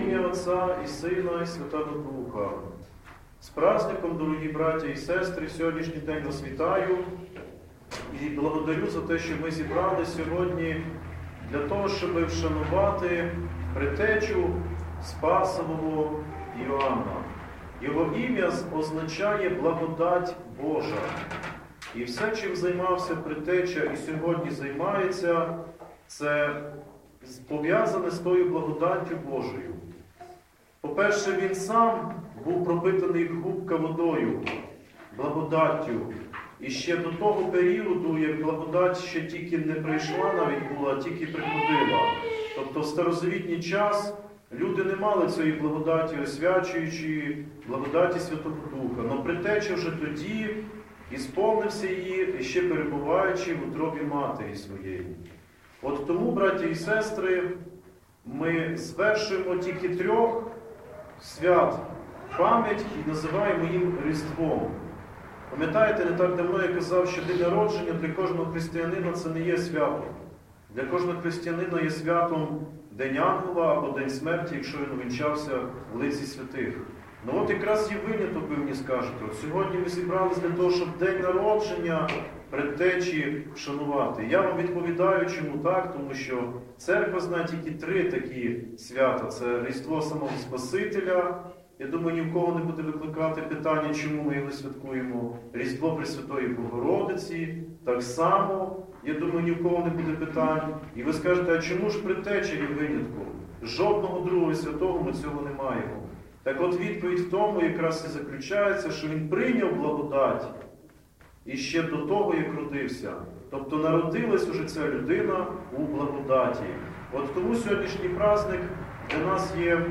Проповідь в День Різдва Предтечі Хрестителя Іоанна – Храм Святителя Іоанна Шанхайського і Сан-Франциського м. Ужгорода